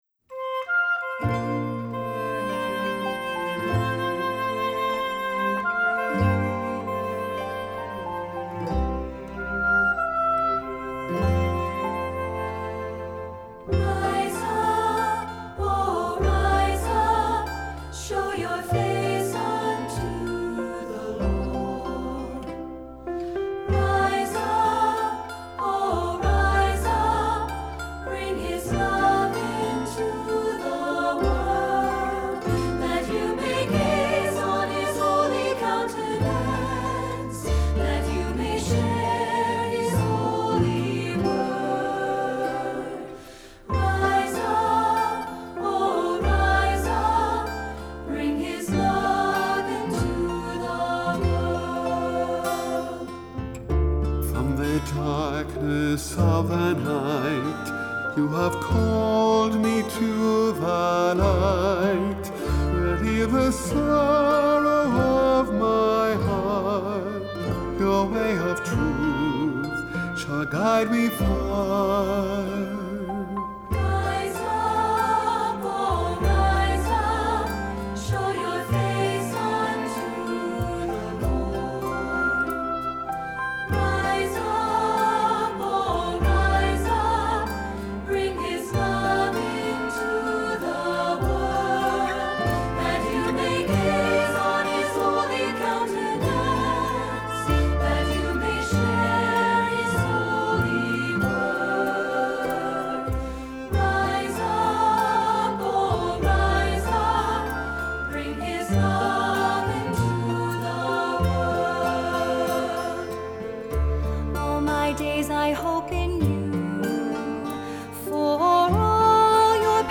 Accompaniment:      Keyboard, Oboe;Cello
Music Category:      Christian